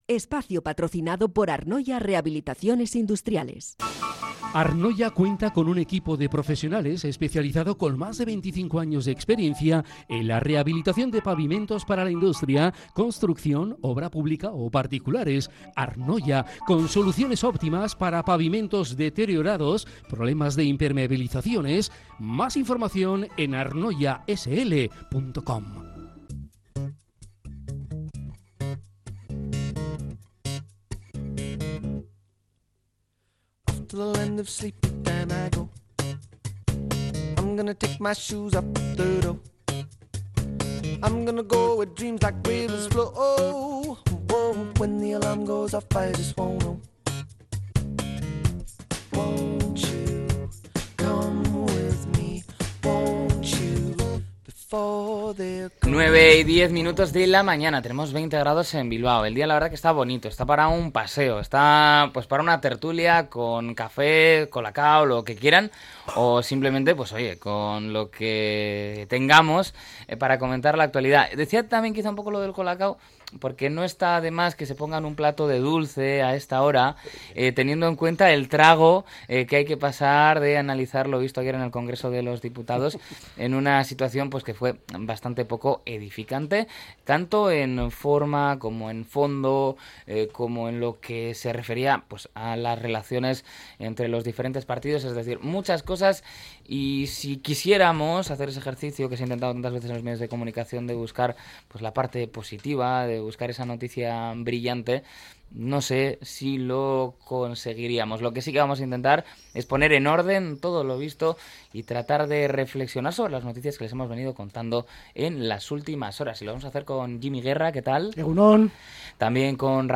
La tertulia 10-07-25.